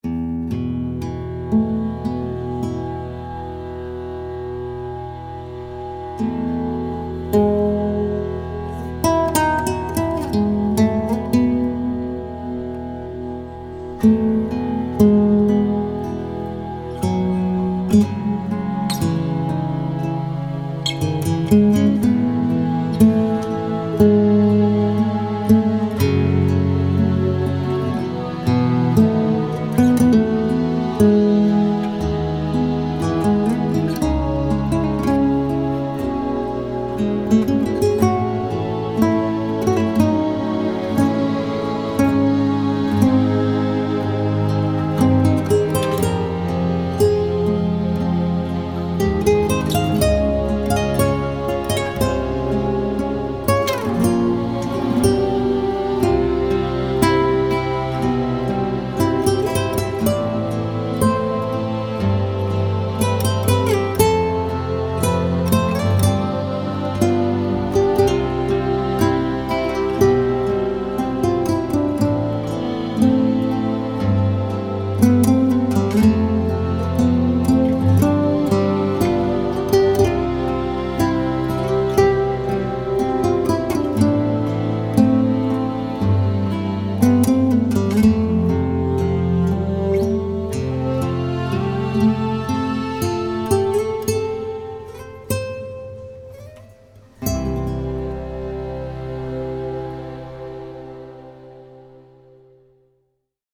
capri-interlude-for-guitar-and-strings.mp3